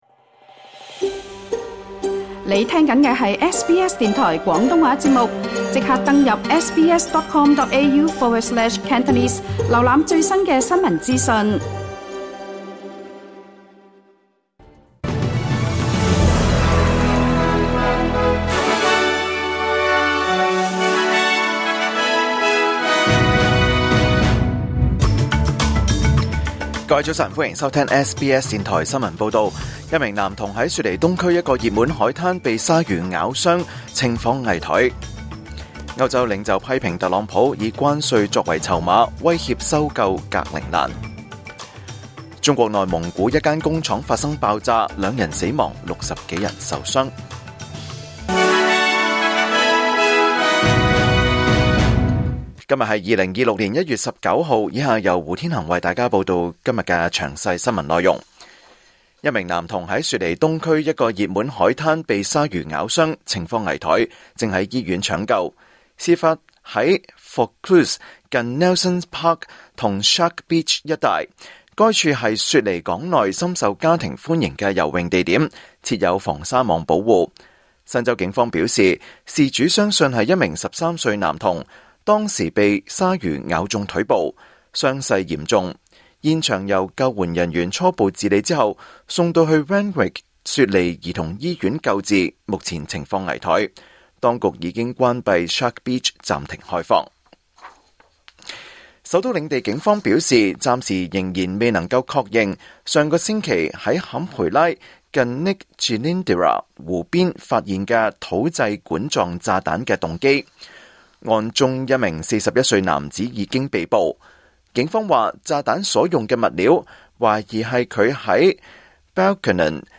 2026年1月19日SBS廣東話節目九點半新聞報道。